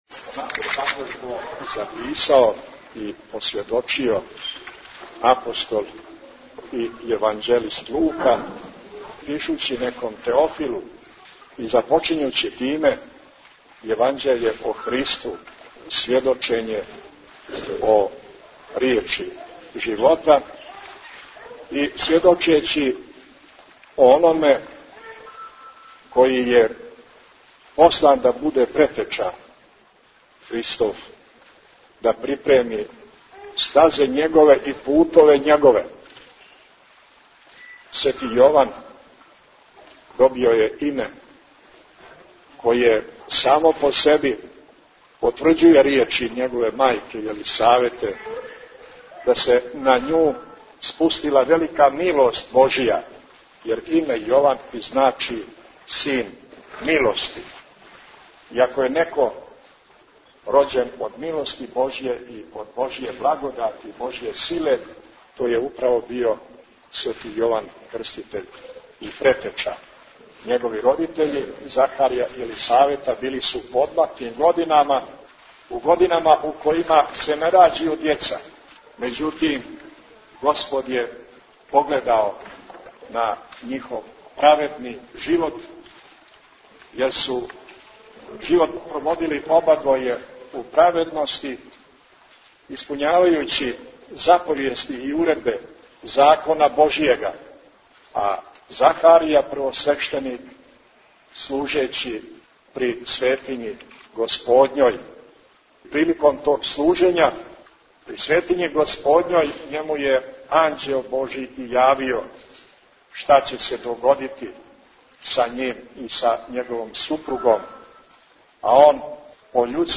Бесједе
Преузмите аудио датотеку 712 преузимања 60 слушања Митрополит Г. Амфилохије служио у храму Рођења Светог Јована Крститеља у Јован долу, 7. јул 2010 Tagged: Бесједе 8:16 минута (1.42 МБ) Празник рођења Светог Јована Крститеља пророка и Претече (сриједа, 7. јул) прослављен је молитвено у храму Рођења Светог Јована Крститеља у Јован долу.
Бројним вјерницима ријечима архипастирске бесједе обратио се Високопреосвећени Митрополит Амфилохије, говорећи о личности Светог Јована Крститеља кога је и сам Господ назвао највећим од жене рођеним.